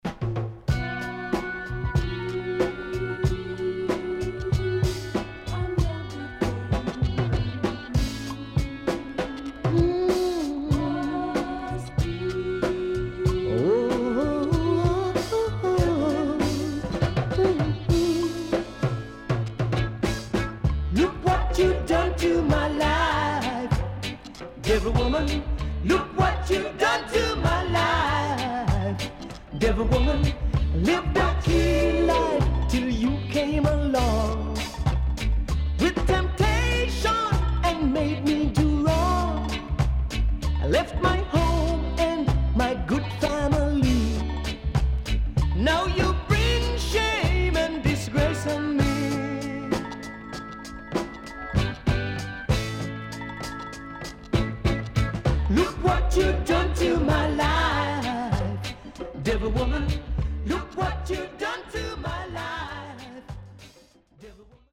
軽快なリズムにソウルフルな歌声